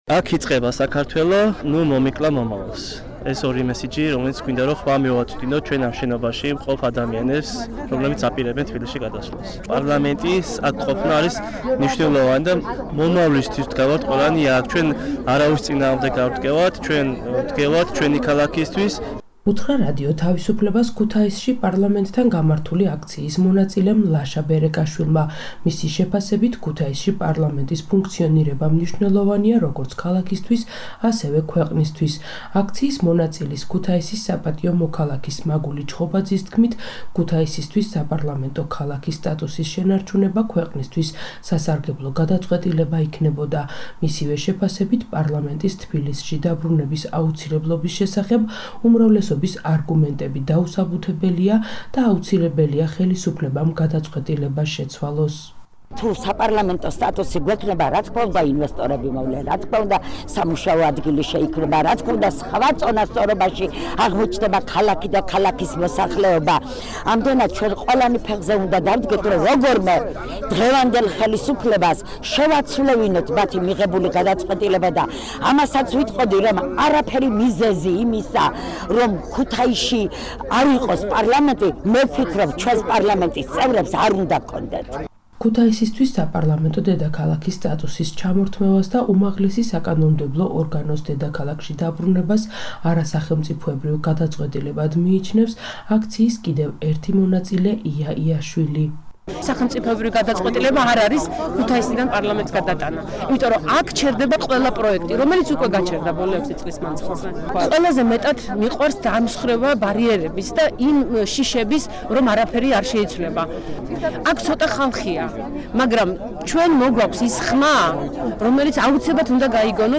საპროტესტო აქცია „დამიტოვე პარლამენტი ქუთაისში!“